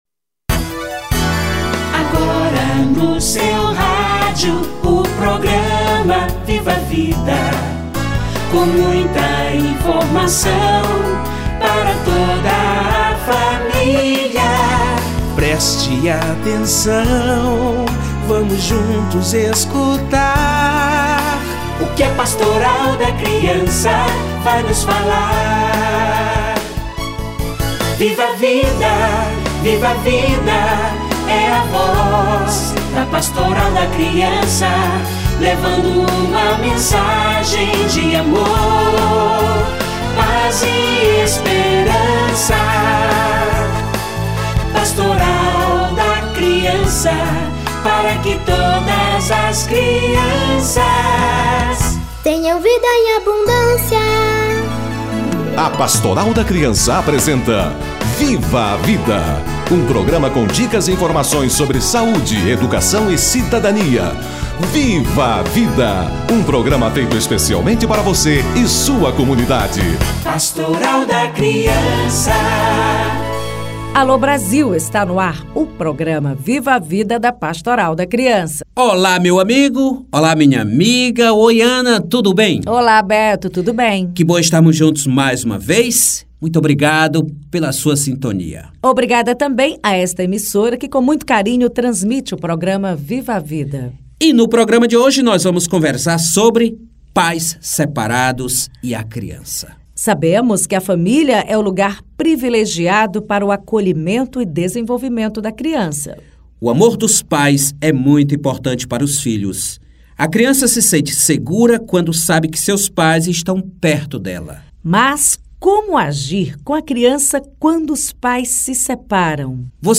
Pais separados e a criança - Entrevista